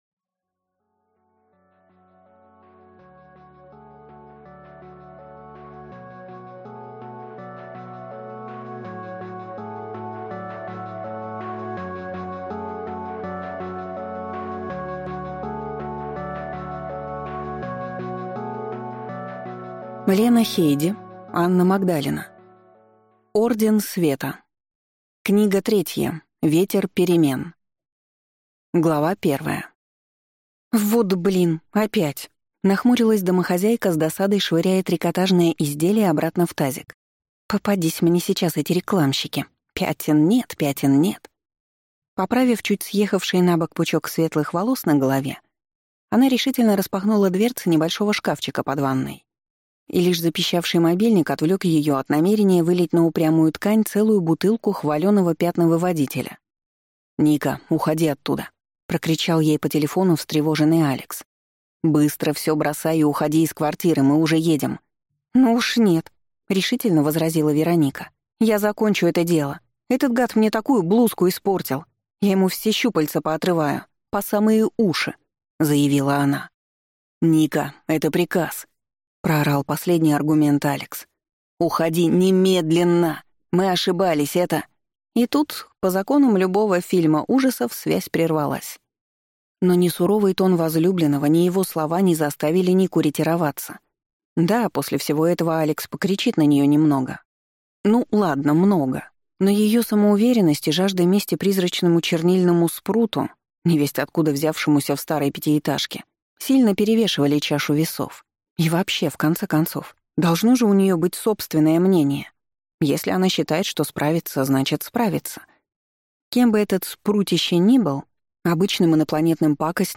Аудиокнига Орден Света. Книга 3. Ветер перемен | Библиотека аудиокниг